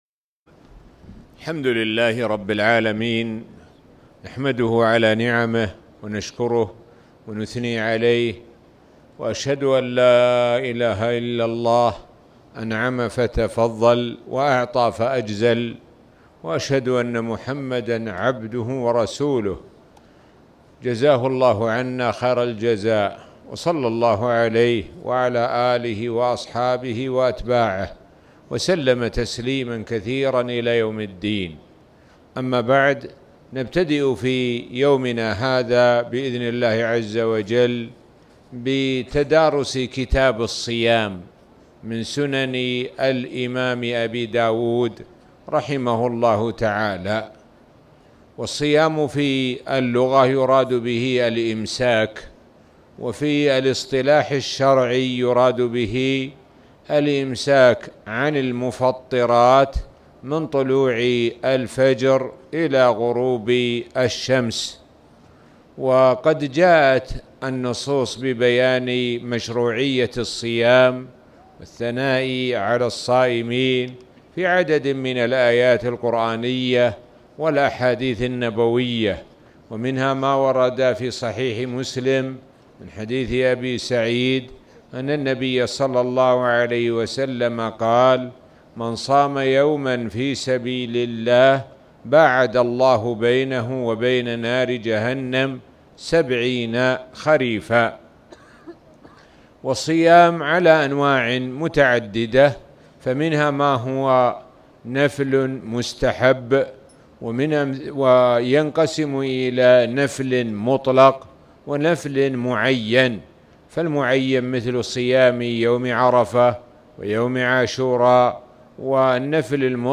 تاريخ النشر ١٩ رمضان ١٤٣٨ هـ المكان: المسجد الحرام الشيخ: معالي الشيخ د. سعد بن ناصر الشثري معالي الشيخ د. سعد بن ناصر الشثري أول كتاب الصيام